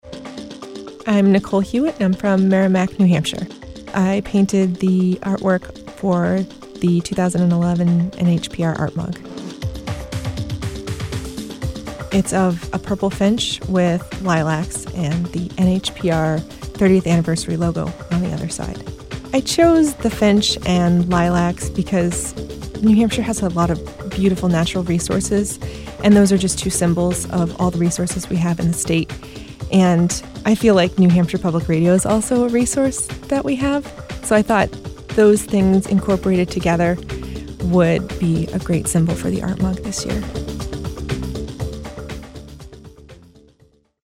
NHPR Interview Clips